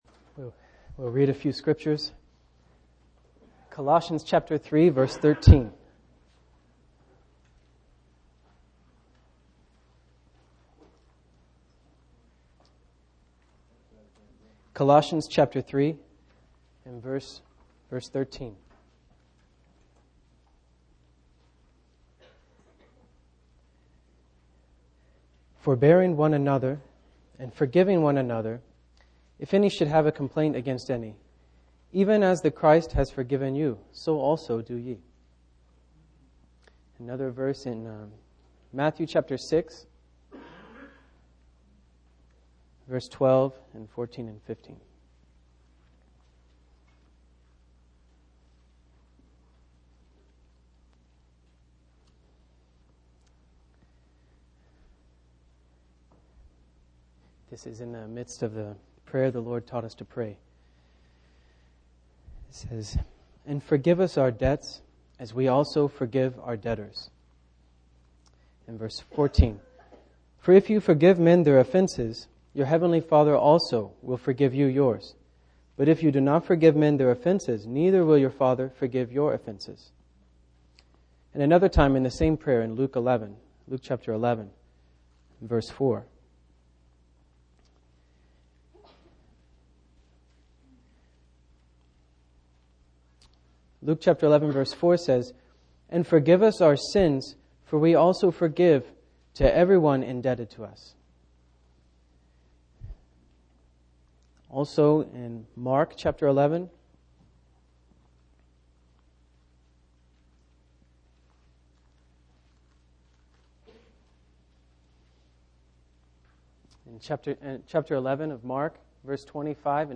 A collection of Christ focused messages published by the Christian Testimony Ministry in Richmond, VA.
Richmond, Virginia, US